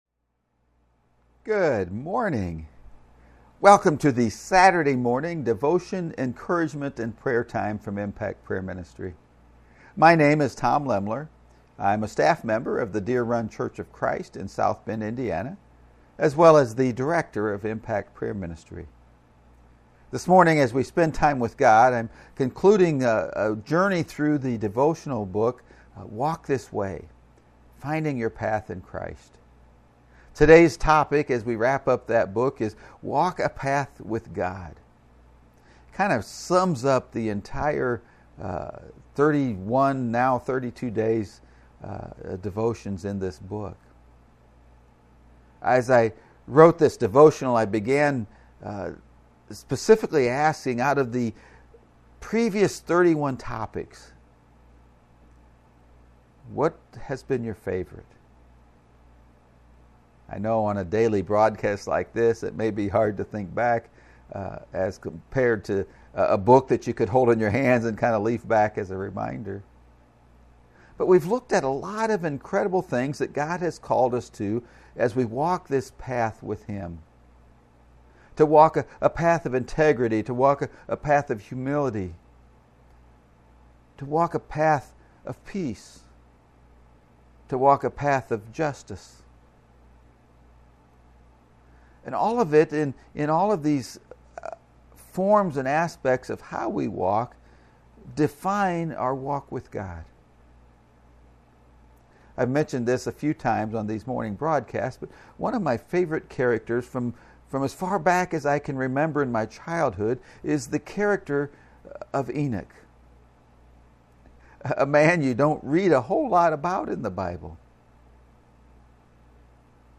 You can find the live video feeds of these encouragement and prayer times on Impact Prayer Ministry’s Facebook page and YouTube channel.